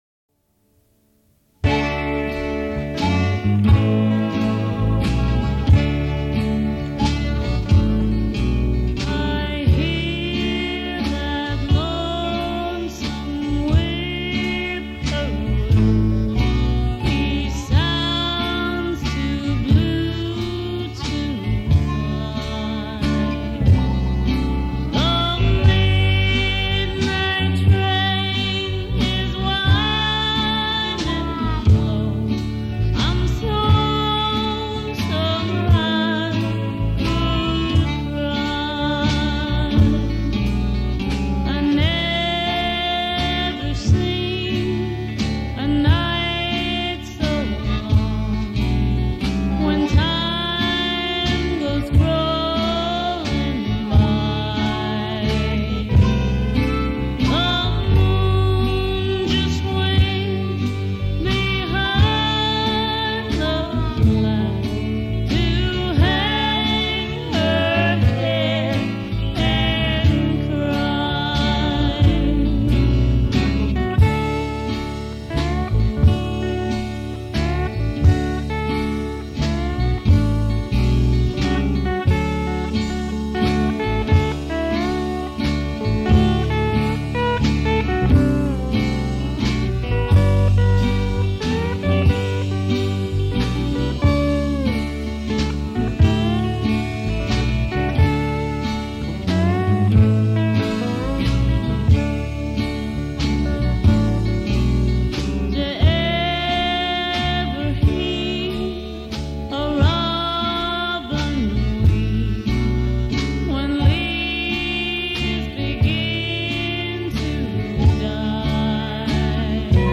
banjo, harmonica
dobro
electric guitar
electric bass
drums